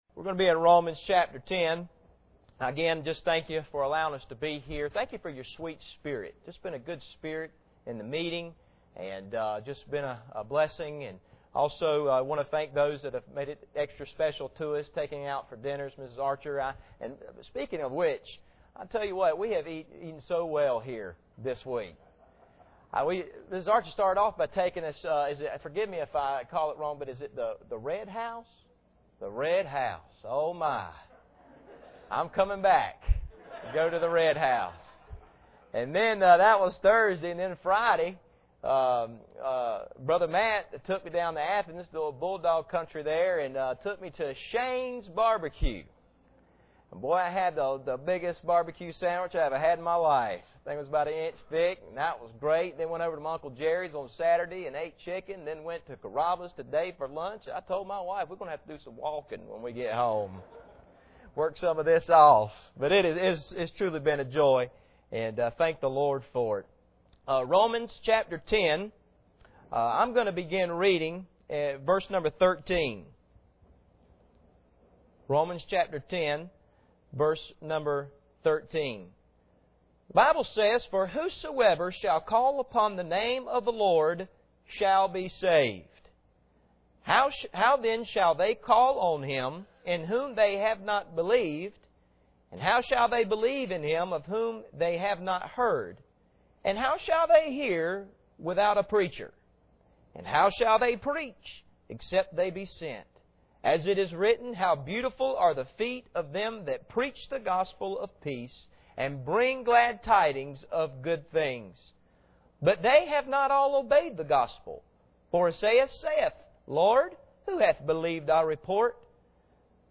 Passage: Romans 10:13-17 Service Type: Sunday Evening